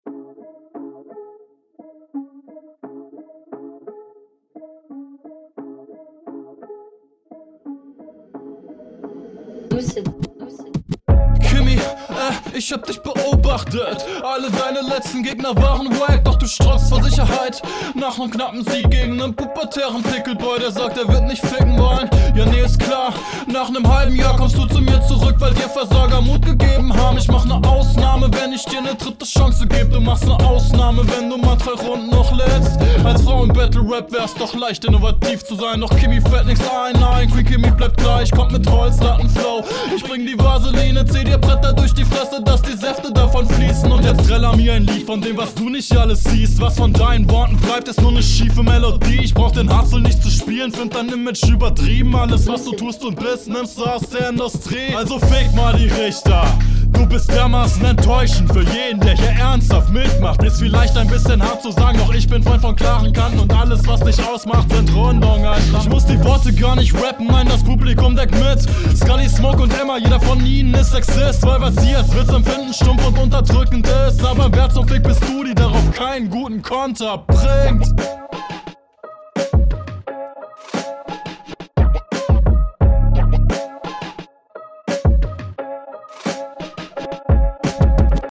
Gute Lines, guter Flow, gute Delivery und und und ich kann mich …
Mische: Klingt sogar noch geiler hier, Beat muss immer noch lauter.